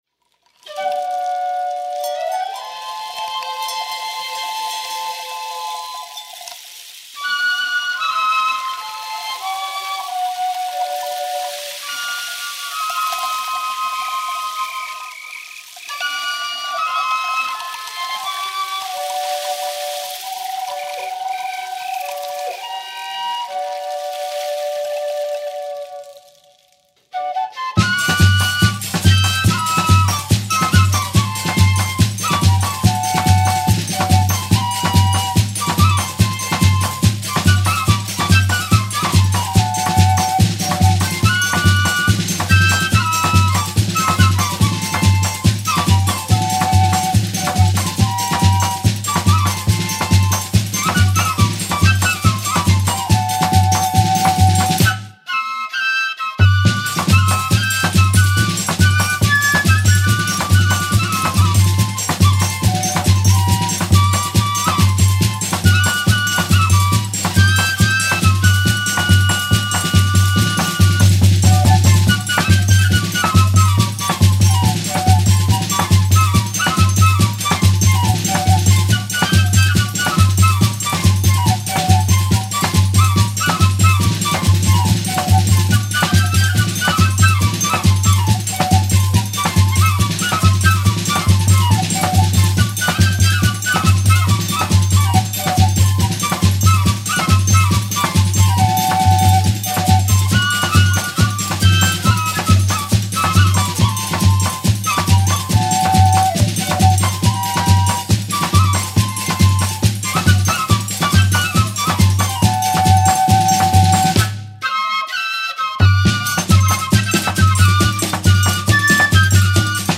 02:41:00   Baião